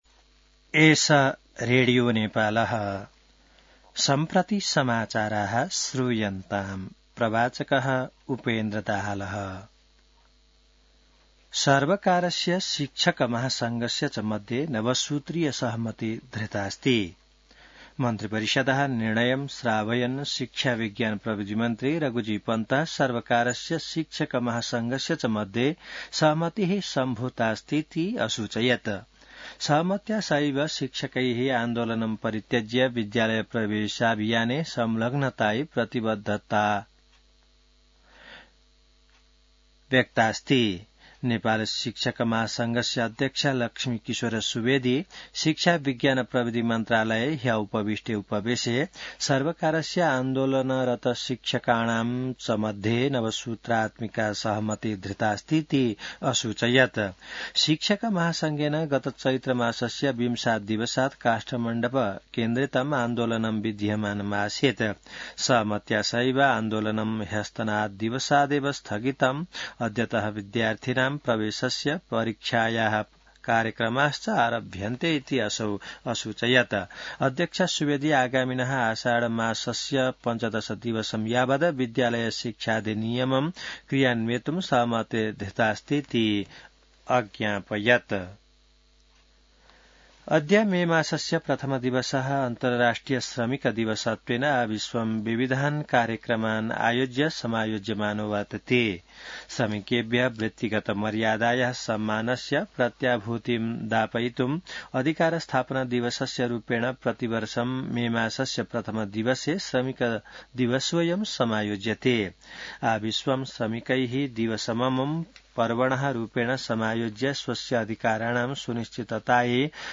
संस्कृत समाचार : १८ वैशाख , २०८२